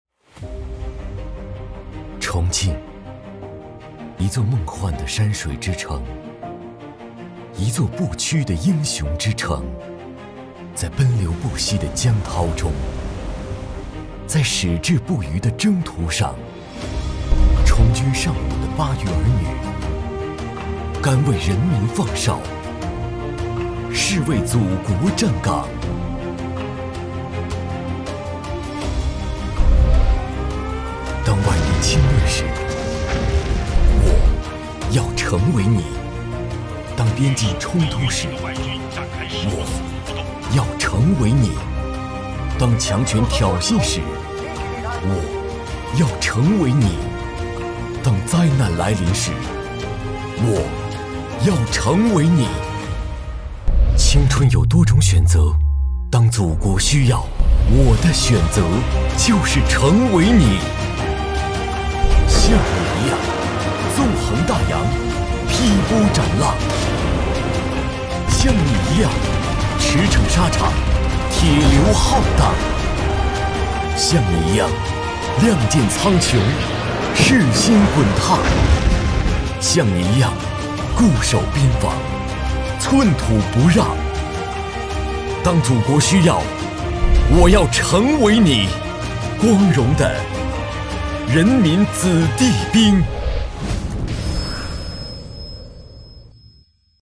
19 男国475_其他_旁白_旁白我要成为你征兵 男国475
男国475_其他_旁白_旁白我要成为你征兵.mp3